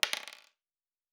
pgs/Assets/Audio/Fantasy Interface Sounds/Dice Single 4.wav at master
Dice Single 4.wav